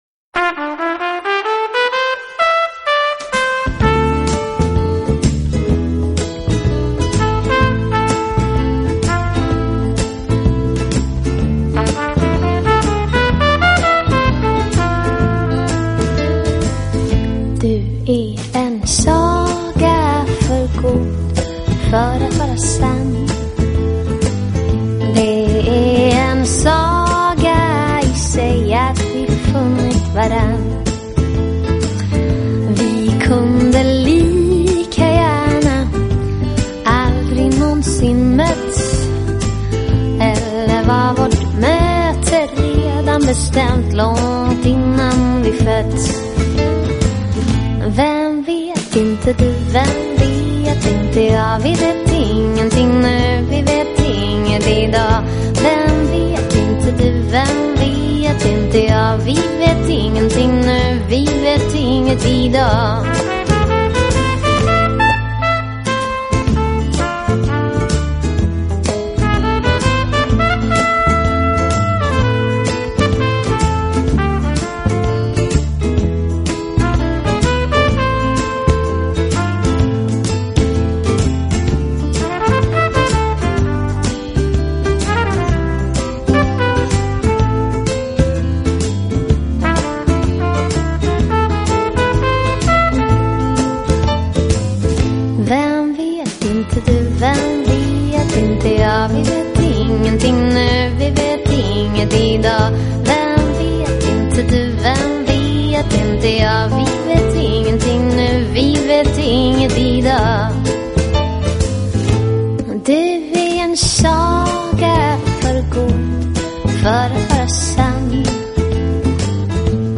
她童稚而沙哑声音习惯在夜晚覆盖整个欧陆。